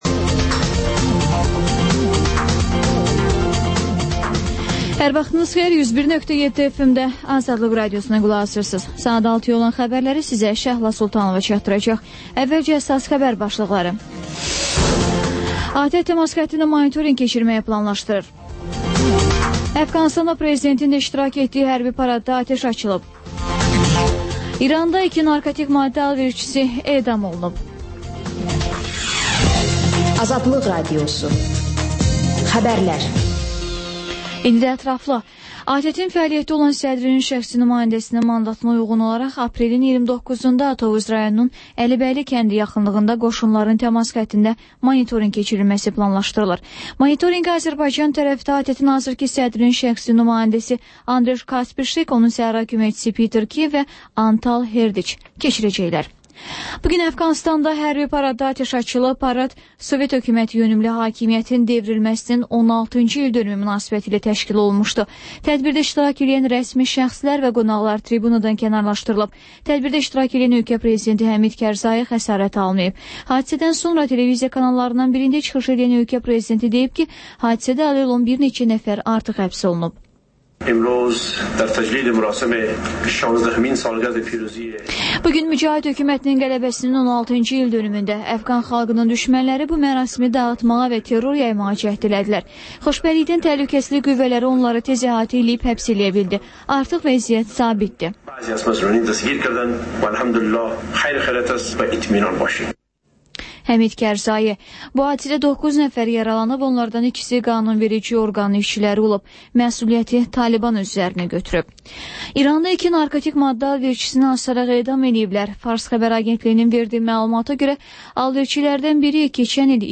Xəbərlər, QAYNAR XƏTT: Dinləyici şikayətləri əsasında hazırlanmış veriliş, sonda 14-24